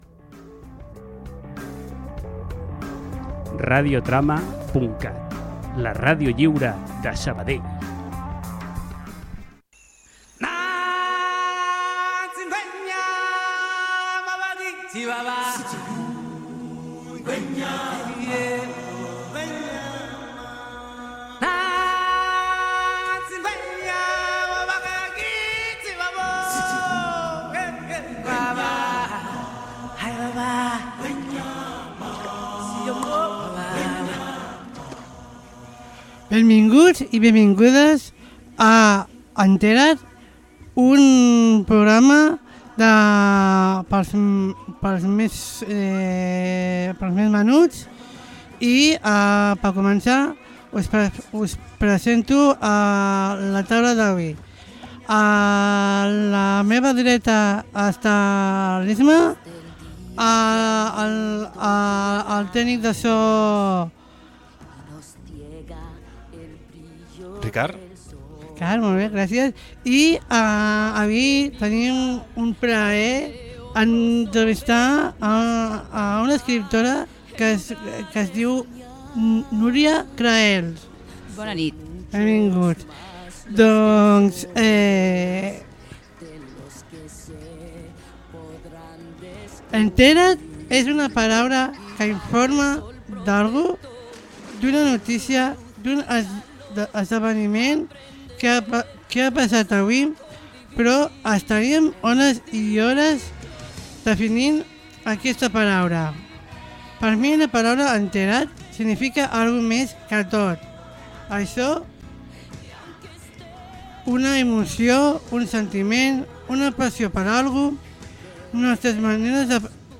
Identificació de l'emissora amb adreça web, presentació del programa, equip, el sentit de la paraula "Entera't", formes de contactar amb el programa.
FM